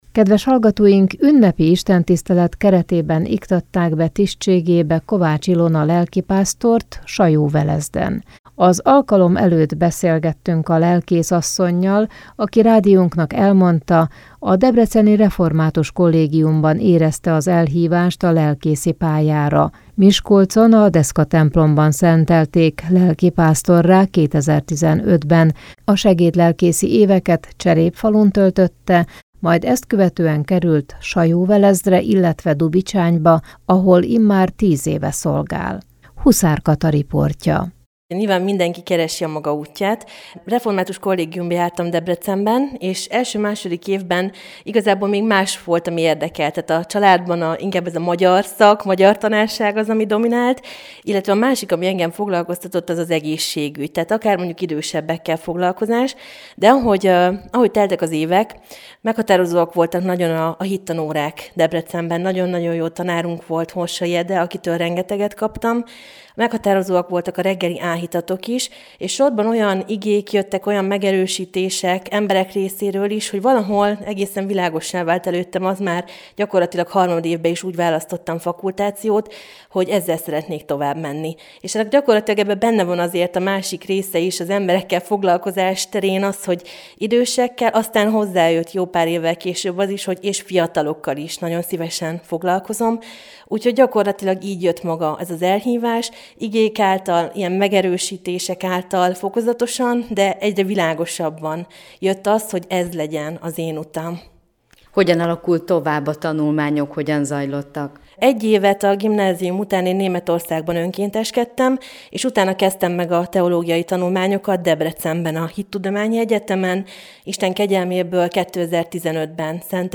Ünnepi istentisztelet és lelkészbeiktatás Sajóvelezden